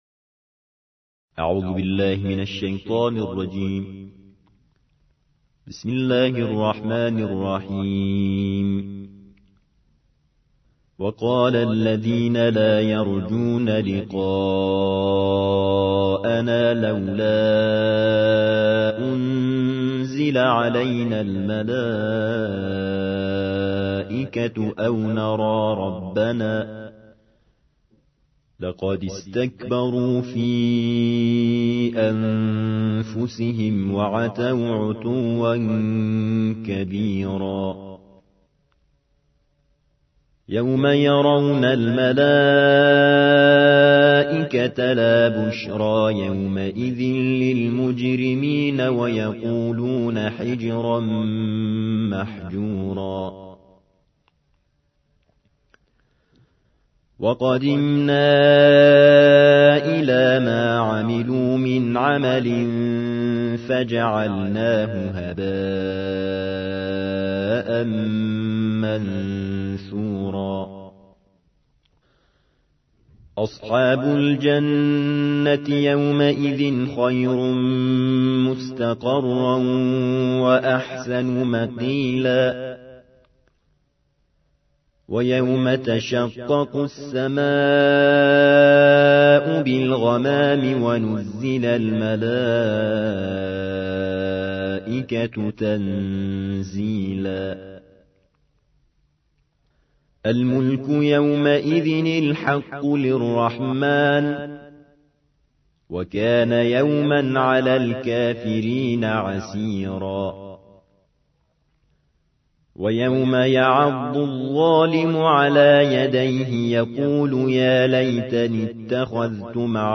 الجزء التاسع عشر / القارئ